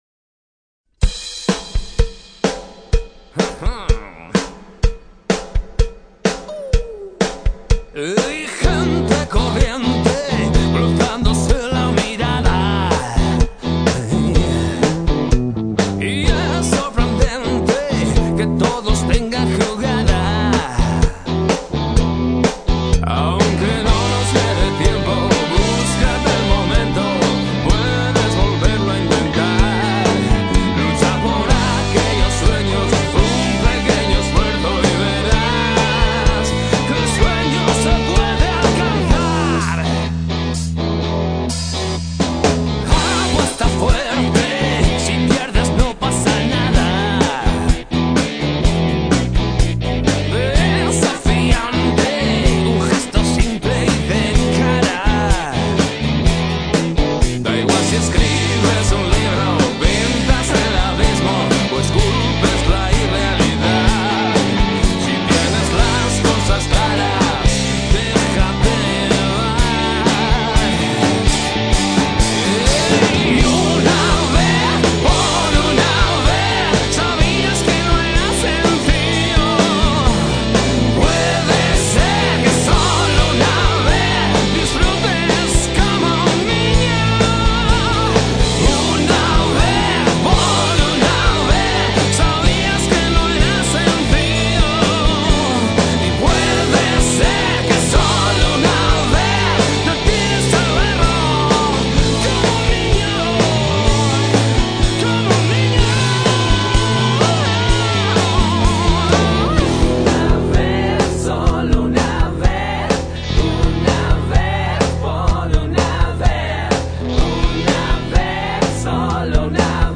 guitarra
batería
coros
(versión demo)